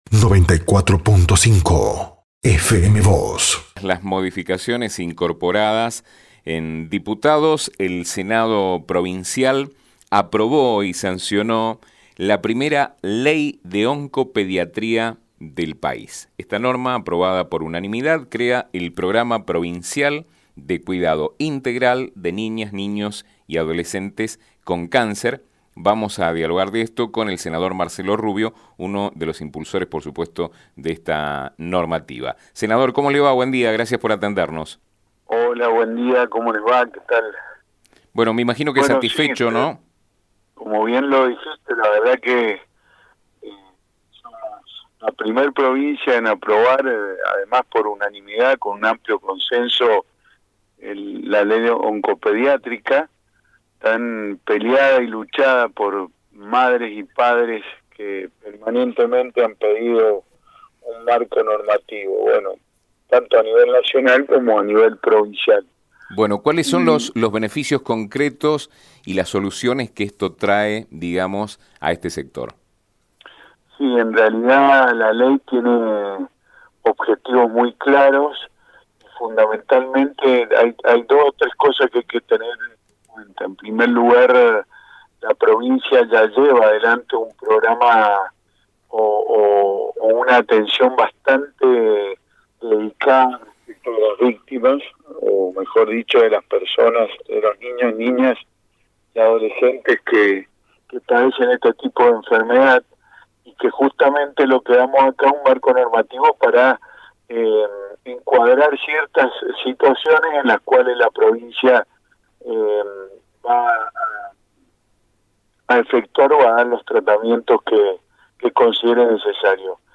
Rubio dijo a FM Vos (94.5) y Diario San Rafael que “somos la primera provincia en aprobar, además por unanimidad, la ley oncopediátrica tan peleada por madres y padres, que permanentemente pedían un marco normativo”.